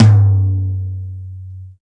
Index of /90_sSampleCDs/Roland L-CD701/KIT_Drum Kits 7/KIT_Loose Kit
TOM ROUNDT05.wav